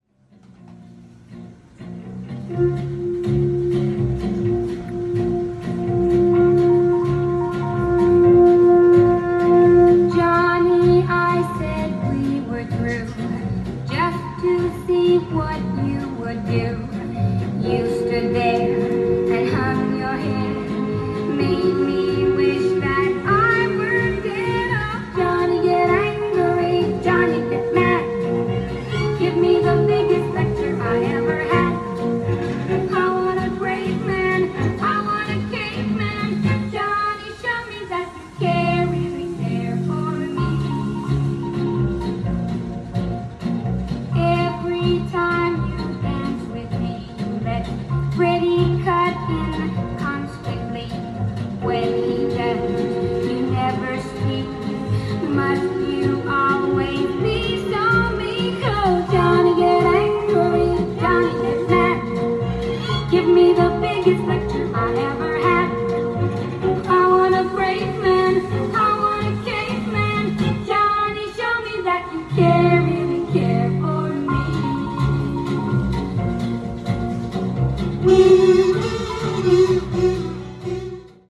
ジャンル：ROCK & POPS
店頭で録音した音源の為、多少の外部音や音質の悪さはございますが、サンプルとしてご視聴ください。